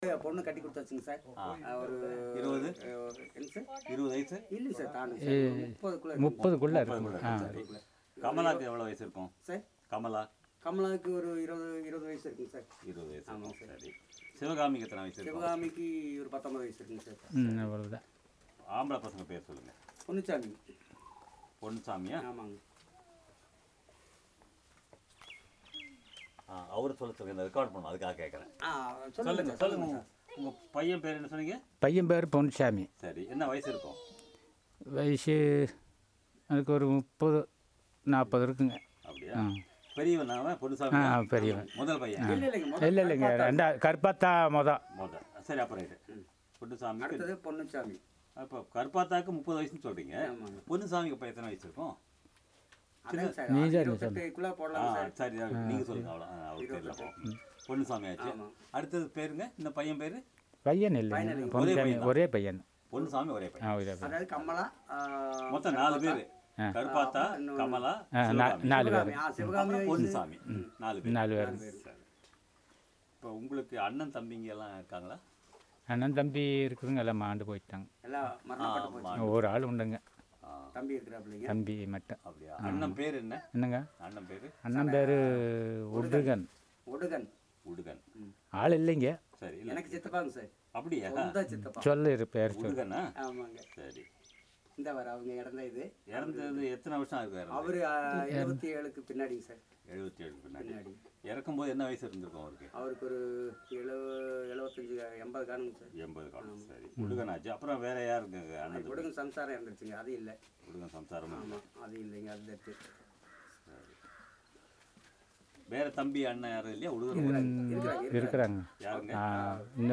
Conversation about the consultant's background